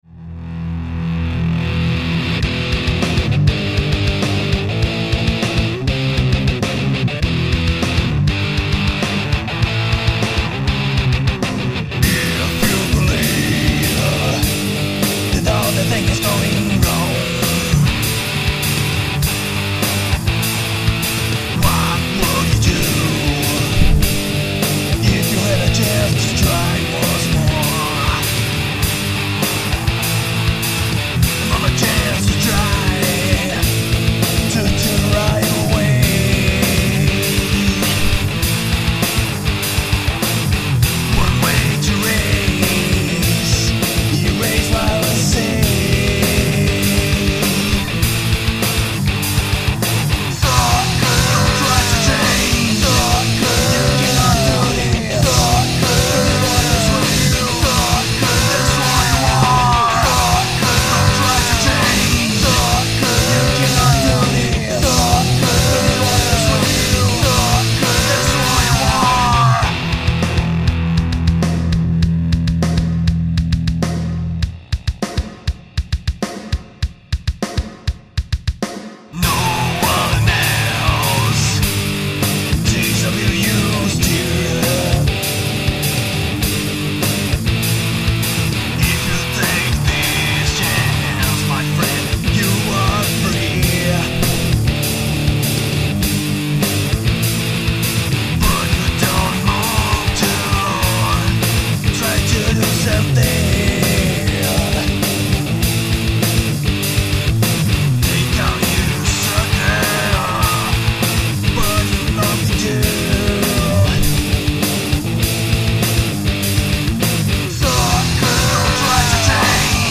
EstiloNew Metal